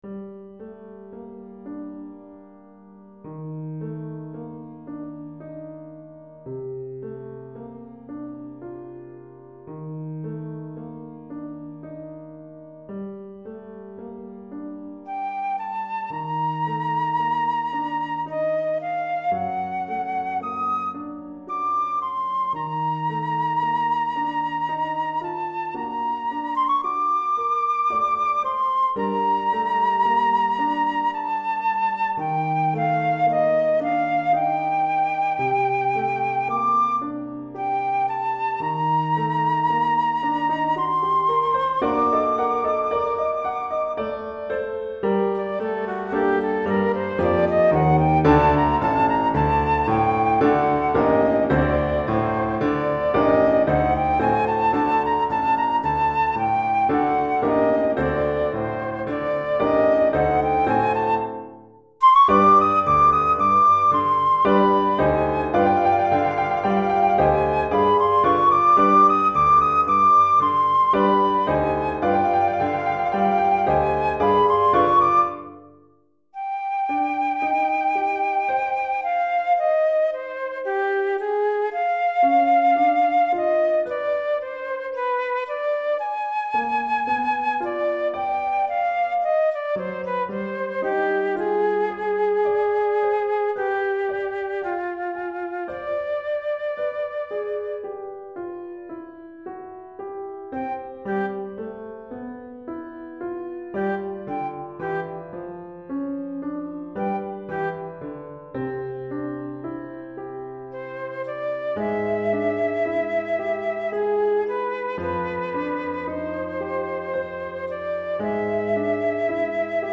Flûte Traversière et Piano